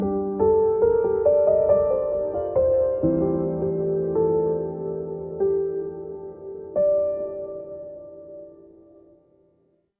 🎵 Background Music
Emotion: longing